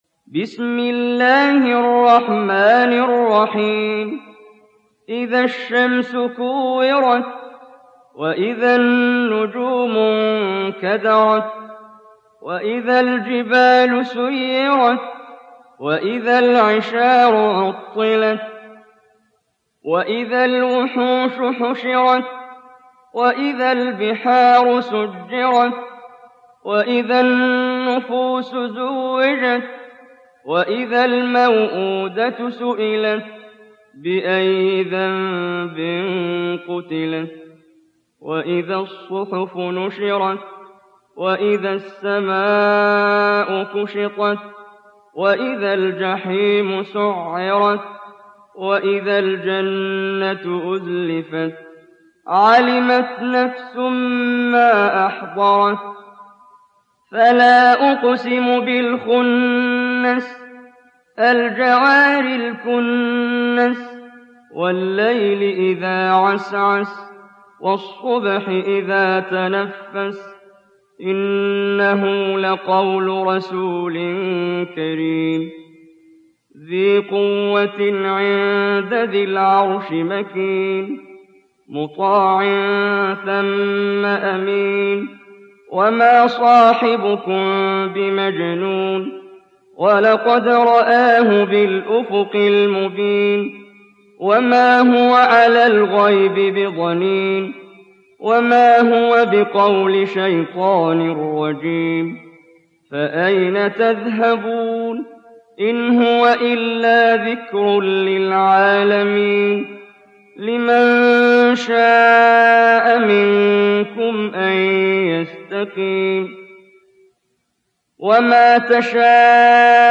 Tekvir Suresi İndir mp3 Muhammad Jibreel Riwayat Hafs an Asim, Kurani indirin ve mp3 tam doğrudan bağlantılar dinle